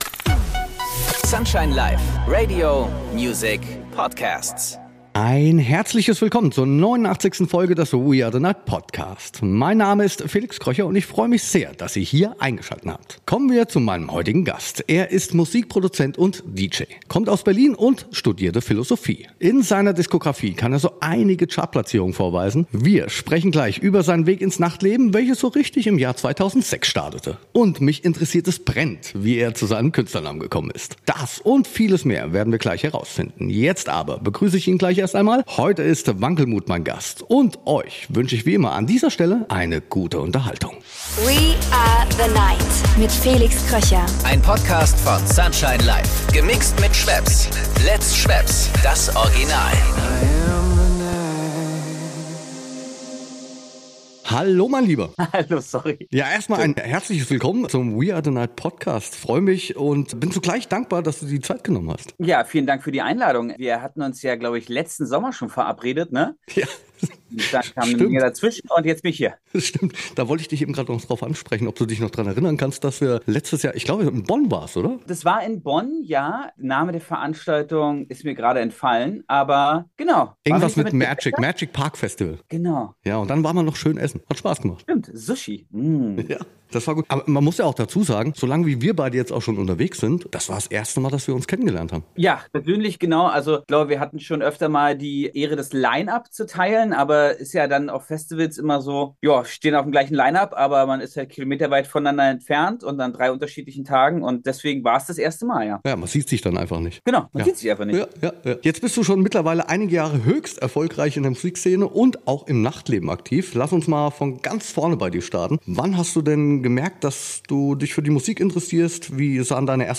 Zusammen mit DJ Kolleginnen, Veranstaltern, Managerinnen, Türstehern und vielen weiteren Gästen plaudert Felix in diesem Podcast unverblümt über seine Erlebnisse.